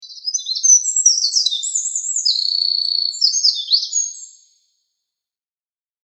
ミソサザイ.mp3